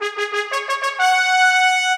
timeout_warning.wav